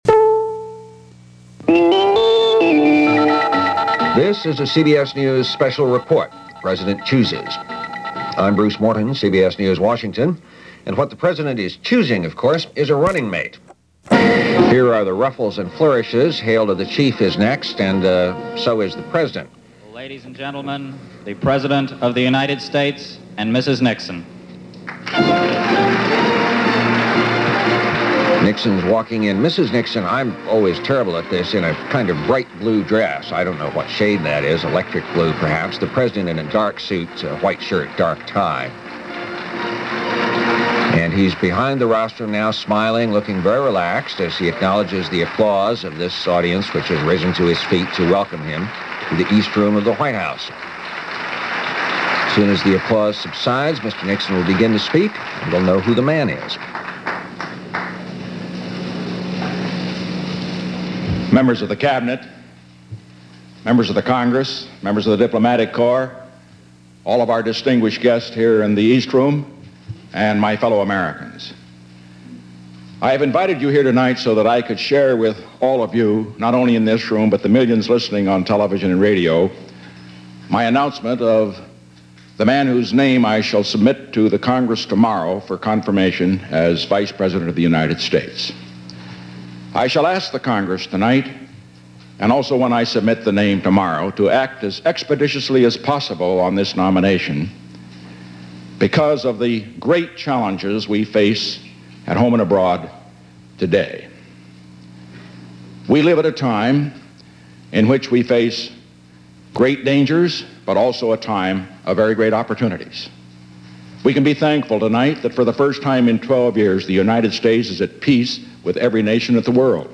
October 12, 1973 - Who Is Gerald Ford? - Nixon Appoints A New VP - - news for this day in history as reported by CBS News.